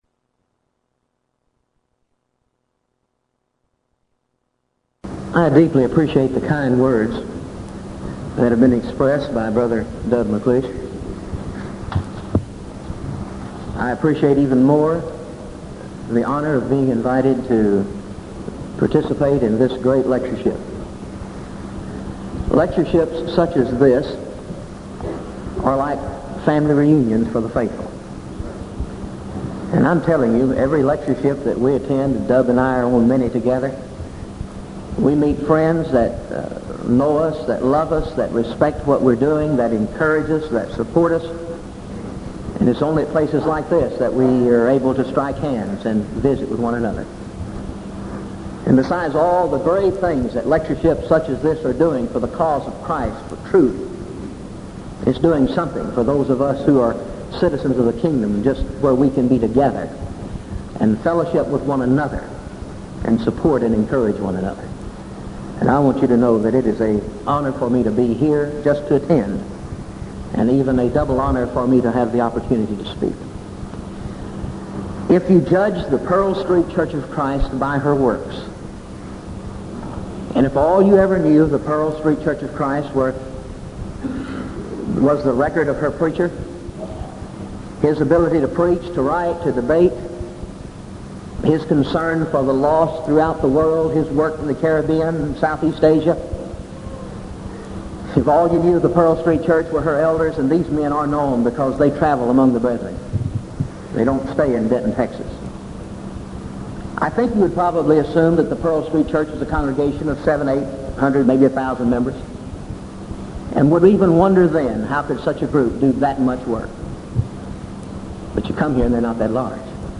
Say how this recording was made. Event: 1987 Denton Lectures